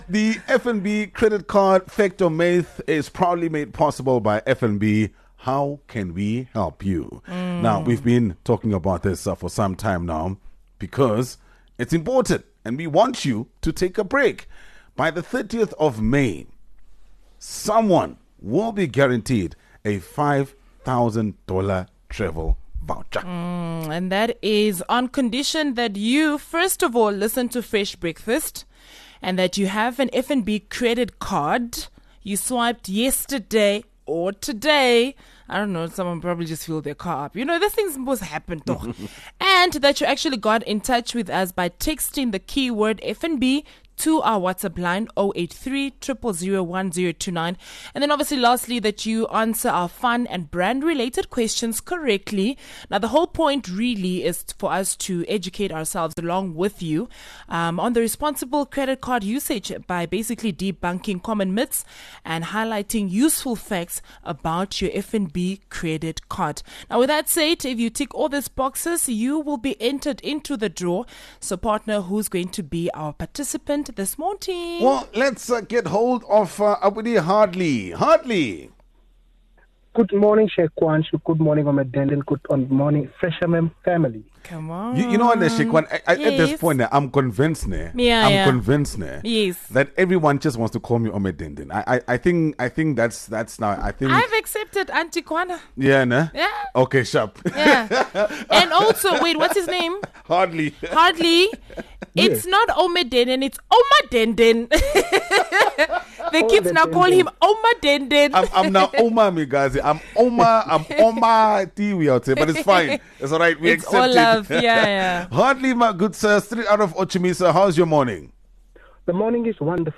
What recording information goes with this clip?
Think you’ve got your credit card knowledge on lock? Fresh FM and FNB are here to put you to the test — with a fun, fast-paced segment that’s all about busting myths and rewarding facts! A game in which you could walk away with a N$5,000 travel voucher!